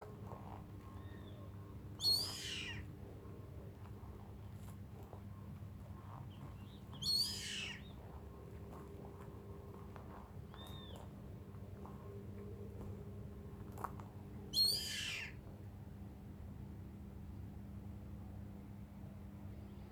Yellow-headed Caracara (Daptrius chimachima)
Detailed location: Lago Salto Grande
Condition: Wild
Certainty: Observed, Recorded vocal